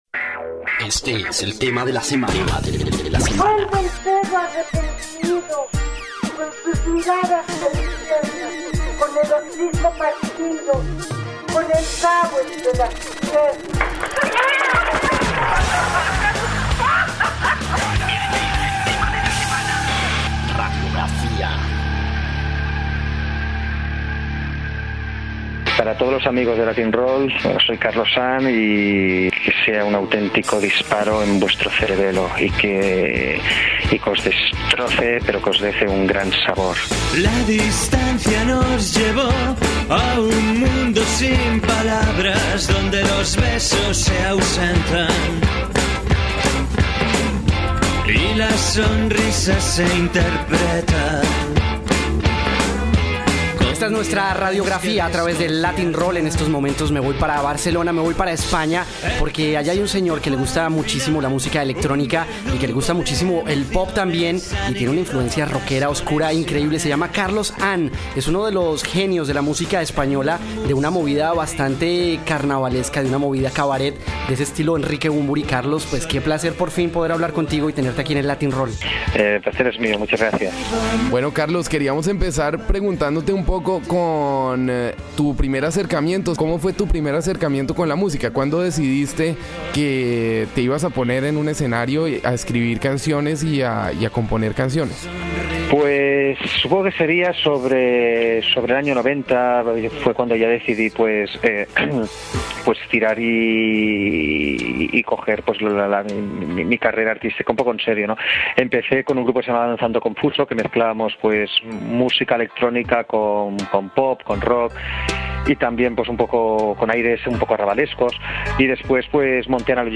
Latin-Roll - Entrevistas